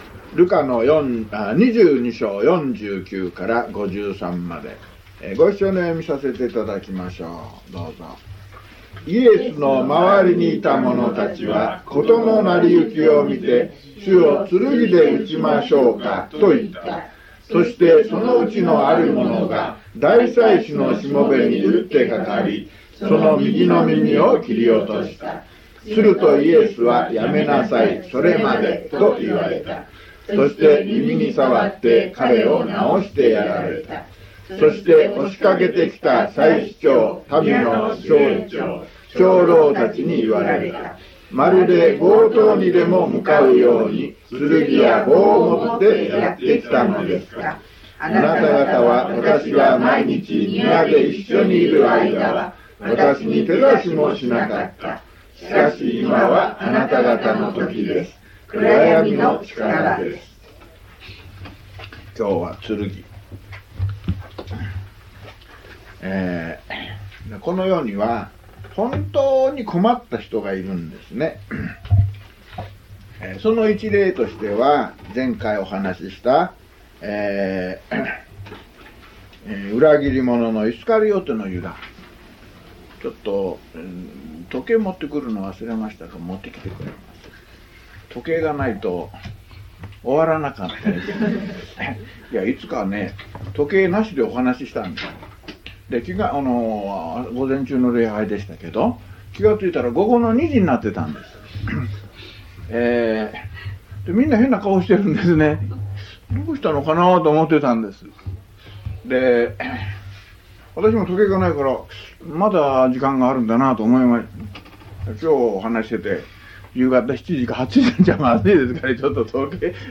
（最後が少し途切れています）
luke180mono.mp3